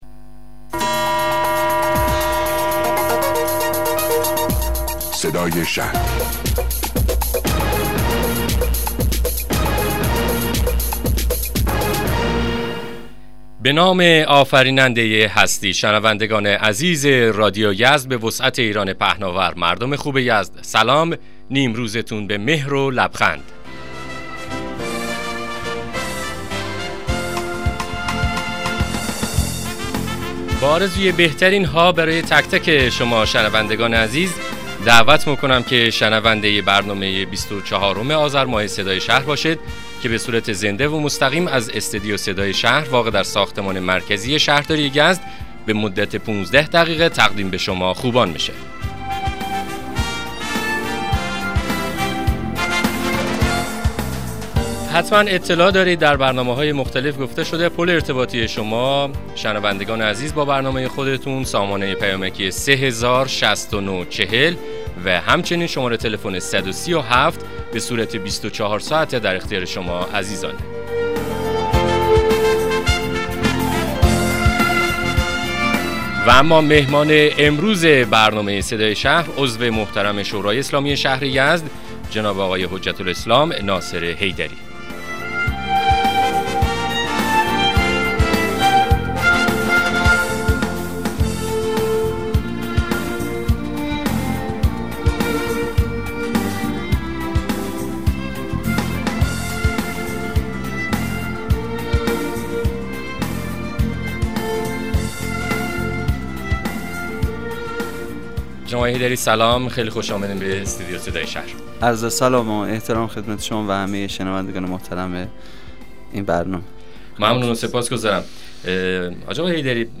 مصاحبه رادیویی برنامه صدای شهر با حضور سید محمد ناصر حیدری رییس کمیسیون فرهنگی ، اجتماعی و ورزشی شورای اسلامی شهر یزد